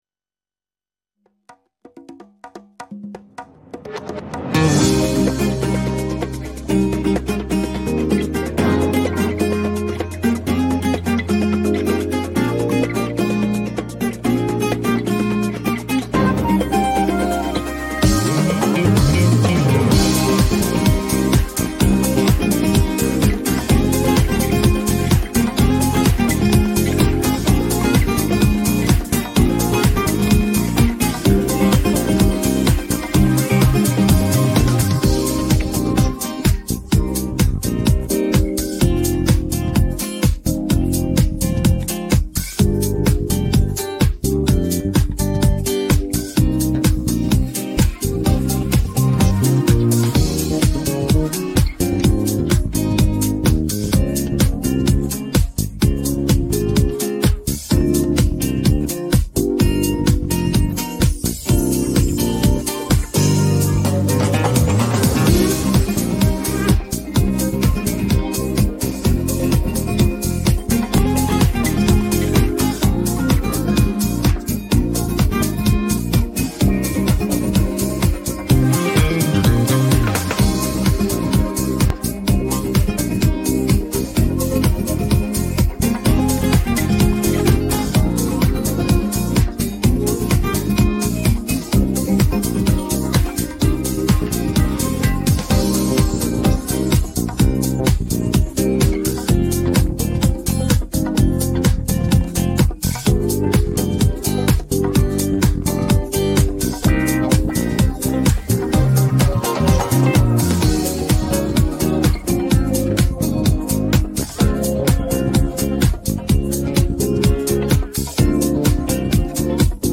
безкоштовна минусовка для караоке онлайн.
українське караоке